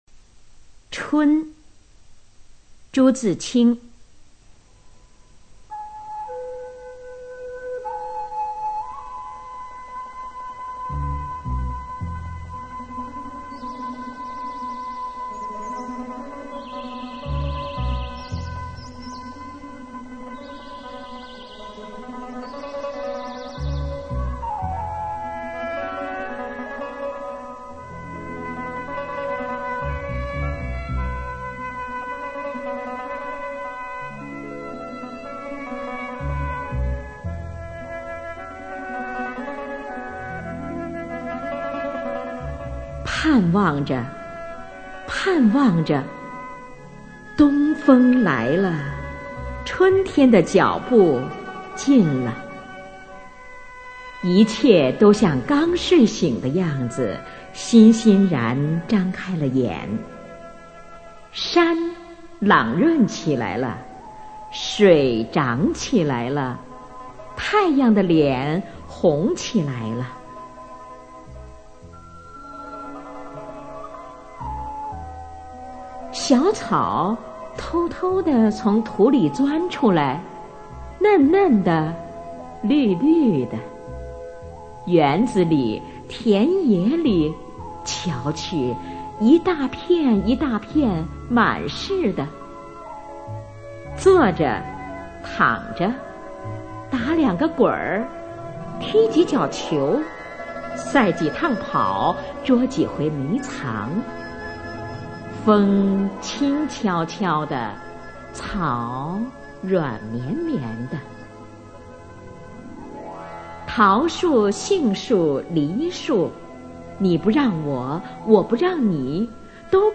《春》课文朗诵